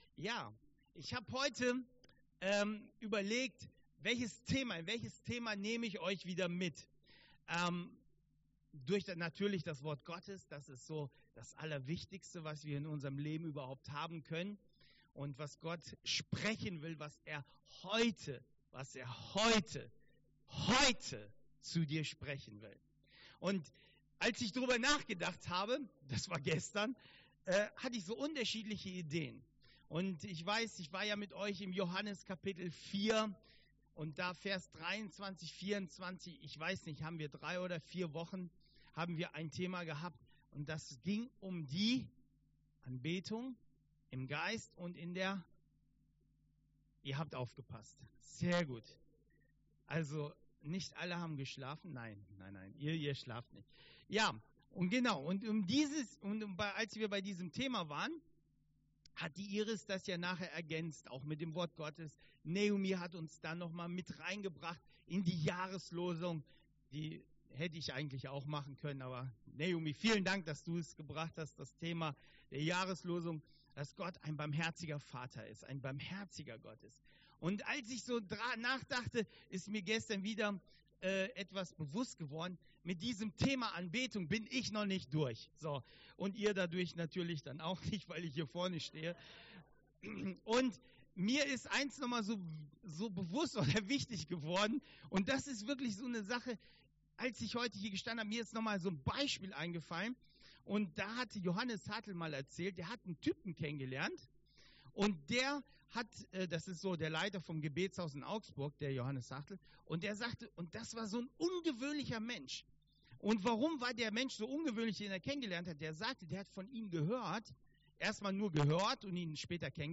Predigt 28.02.2021